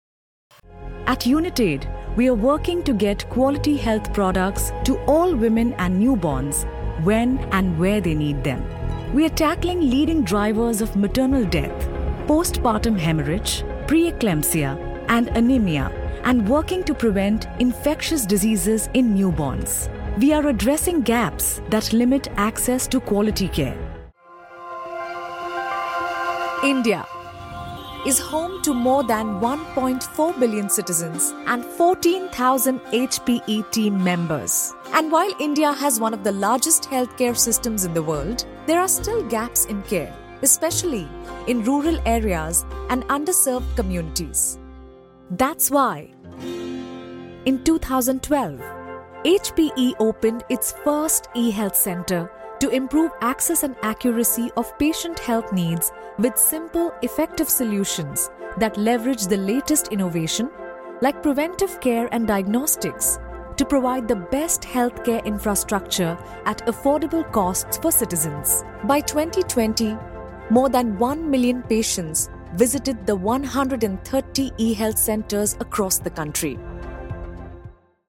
English (Indian)
Medical Narration
With a versatile voice and a broadcast-quality studio, I deliver authentic, edit-friendly recordings with fast turnaround.
Neumann U87, Neumann TSM 102, Neumann TSM 103, AKG C414, Rode NT1 5th Gen